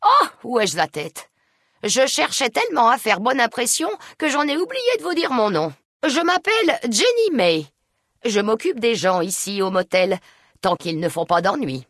Jeannie May Crawford se présentant dans Fallout: New Vegas.